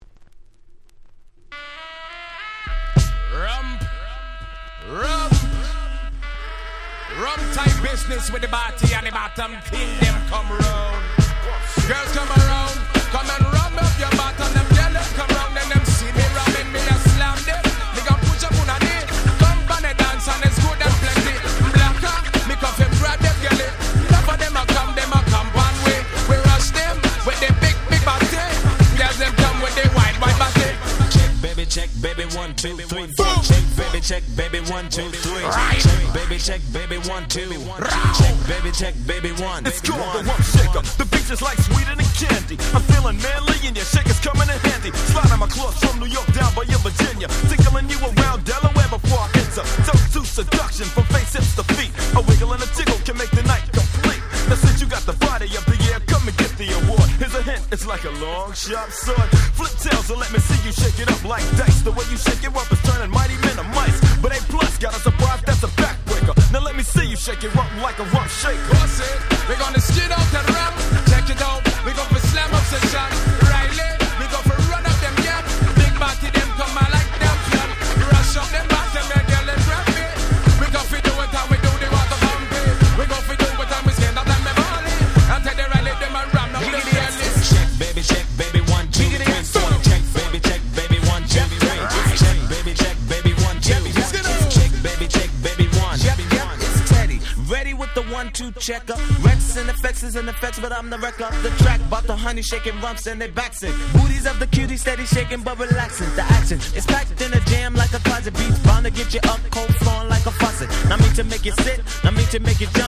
92' Hip Hop Super Classics !!
New Jack Swing